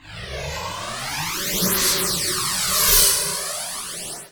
lock1.wav